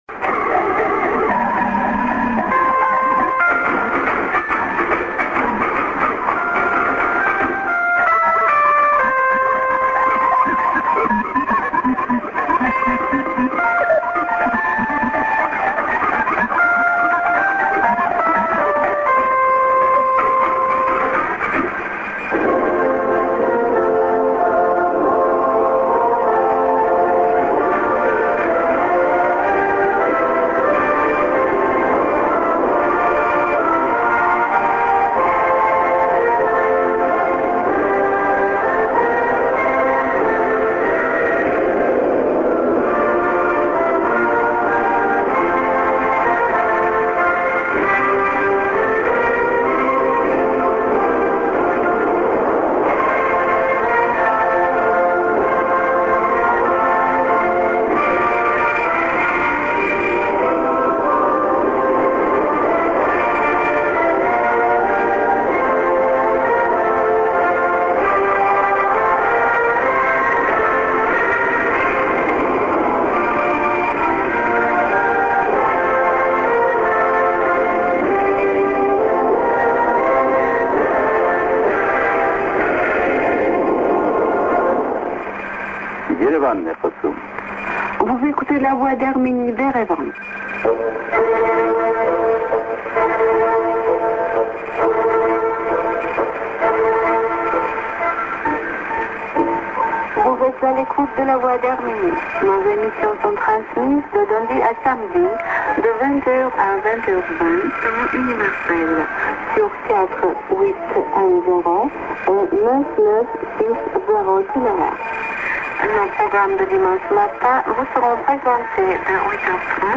IS->NA->ANN(man+women:ID)->ST->ANN(man+women:music+ID+SKJ)->